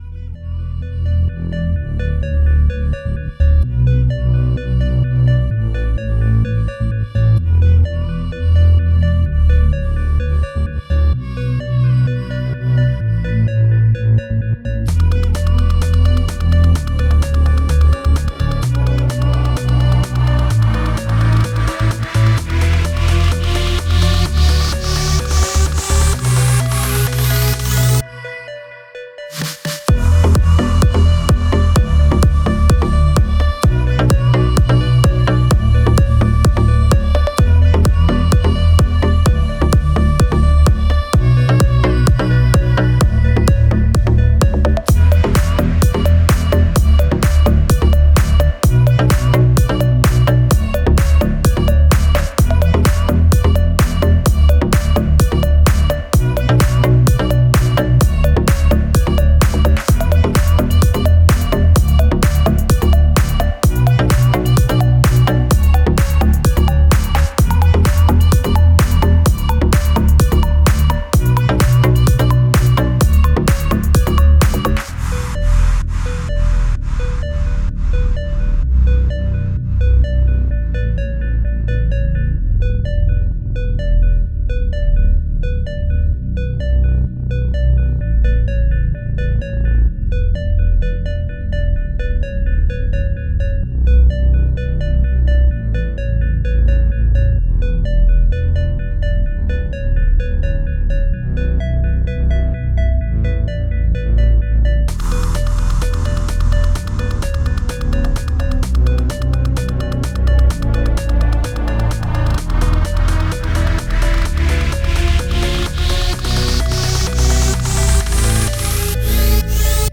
это эмоциональный трек в жанре поп-рок